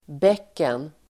Uttal: [b'ek:en]